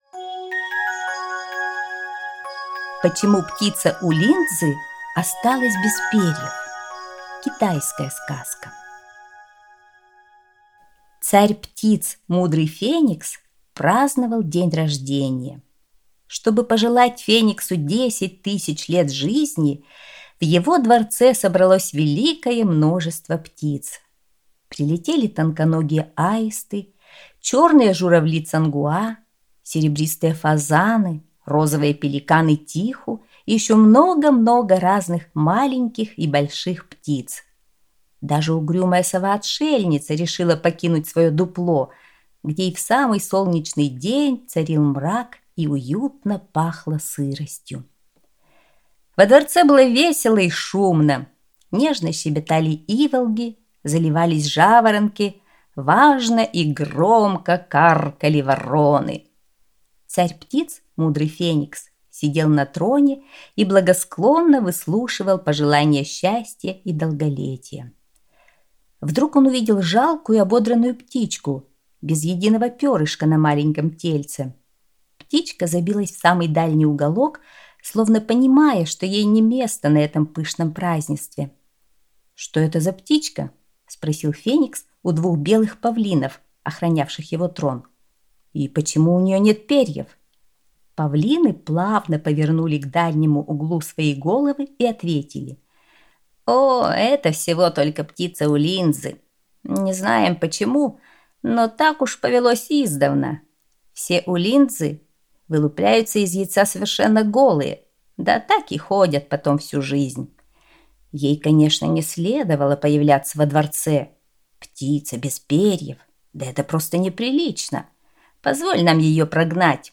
Почему птица улинцзы осталась без перьев – китайская аудиосказка